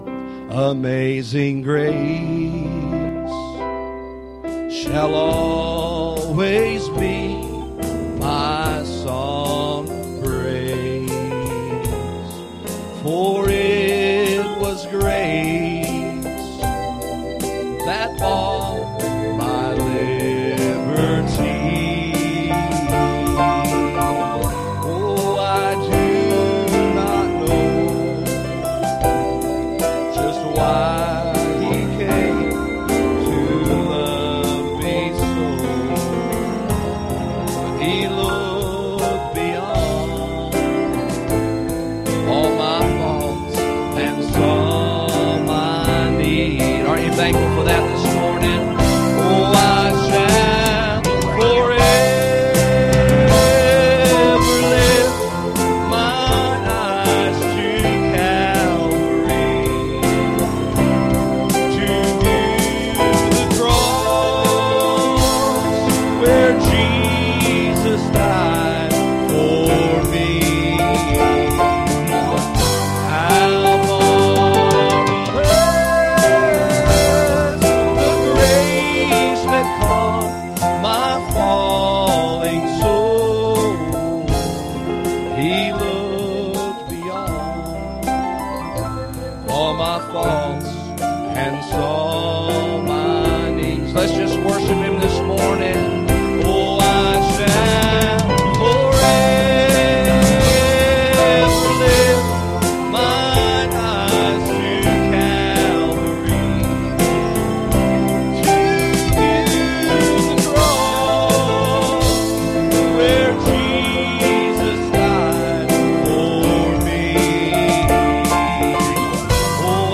Passage: 1 Samuel 17:48 Service Type: Sunday Morning